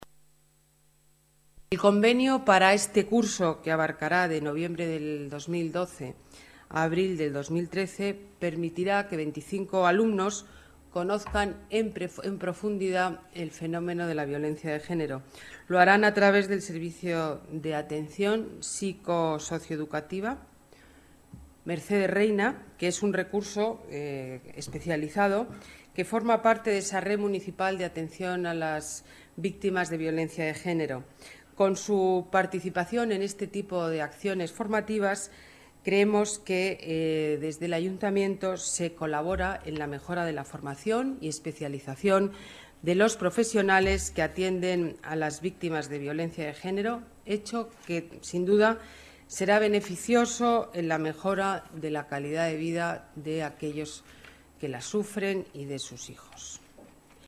Nueva ventana:Declaraciones alcaldesa, Ana Botella: convenio formación psicólogos en violencia de género